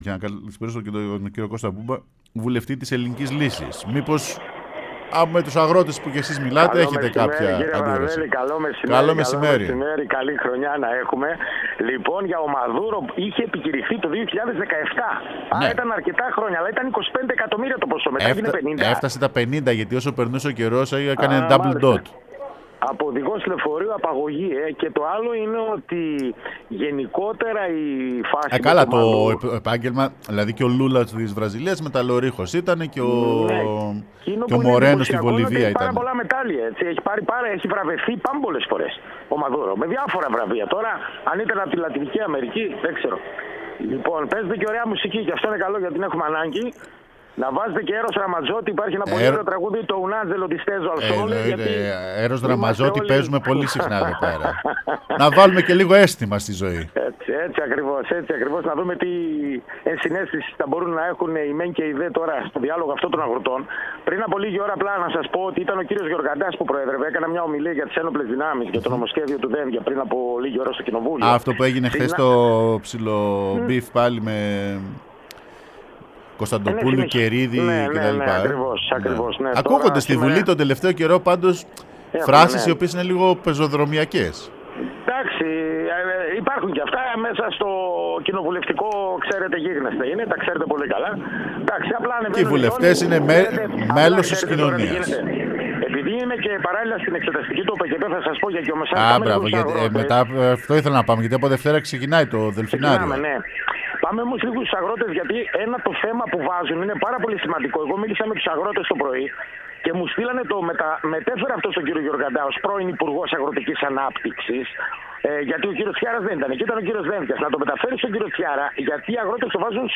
Κώστας Μπούμπας, βουλευτής Ελληνικής Λύσης μίλησε στην εκπομπή Έχουμε και λέμε